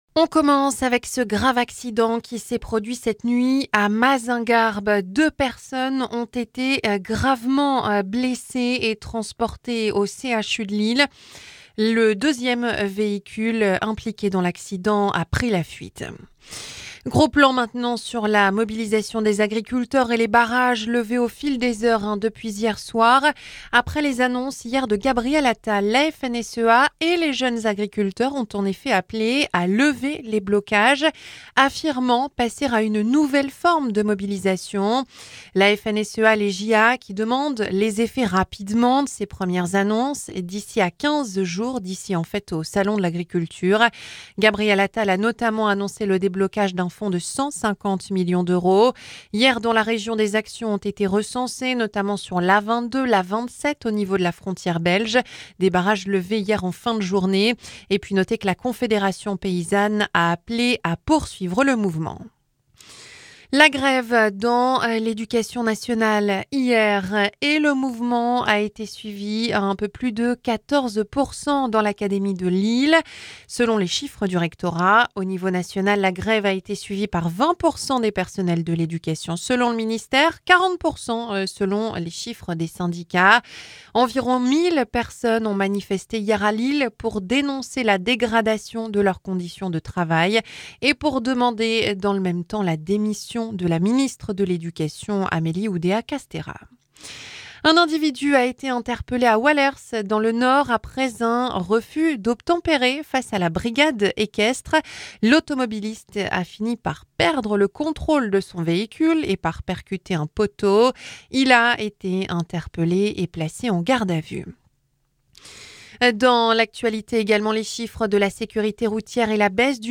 Journal 9h - 2 blessés grave sur la route dans le Pas de Calais